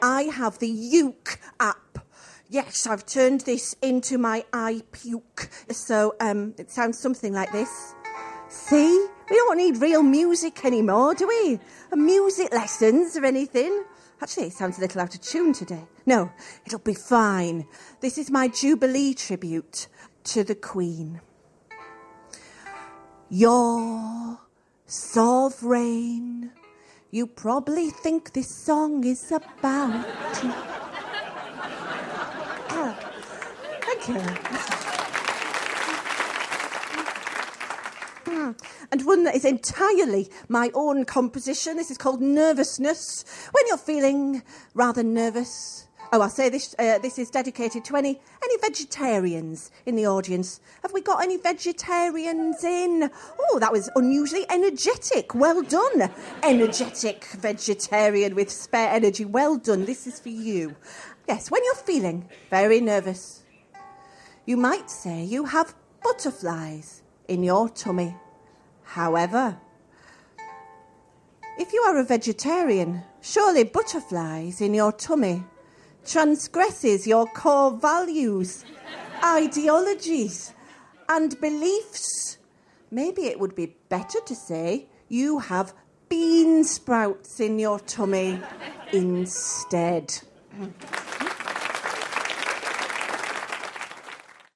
with the accompaniment of her Uke-App, delivered to a studio audience